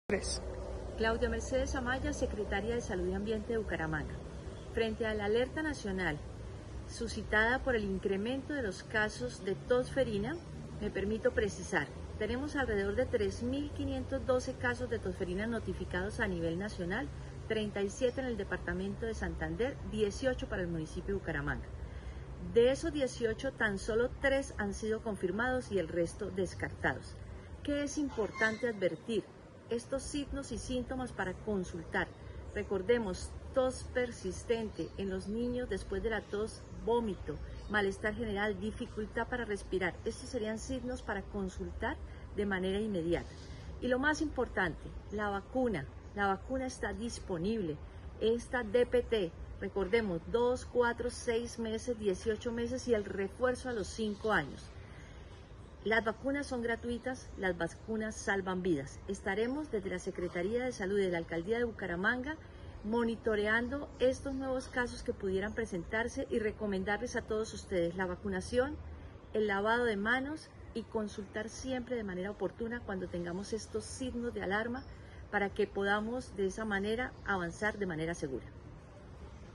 Claudia Amaya, secretaría de Salud de Bucaramanga